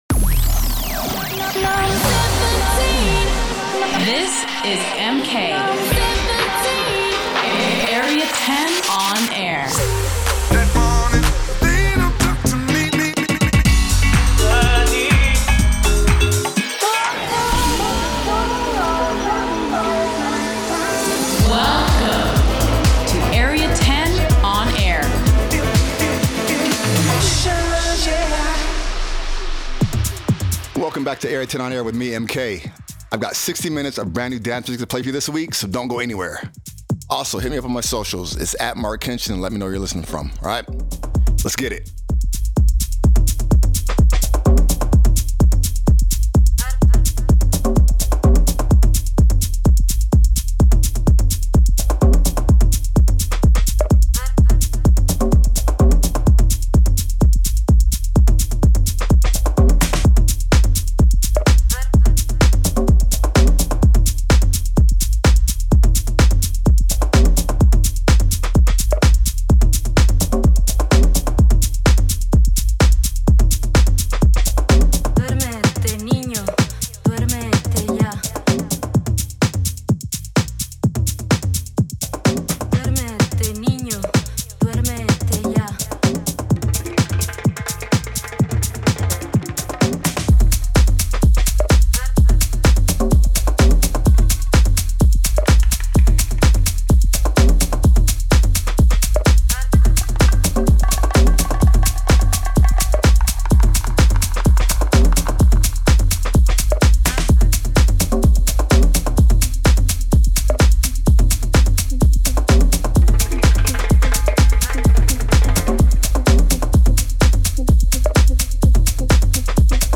Delving in to house and techno, new and old